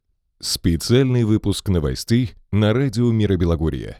Это Slate vms VS Neumann 87 ) Вложения 1.mp3 1.mp3 159,3 KB · Просмотры: 1.896 2.mp3 2.mp3 159,3 KB · Просмотры: 1.880 3.mp3 3.mp3 157,2 KB · Просмотры: 1.853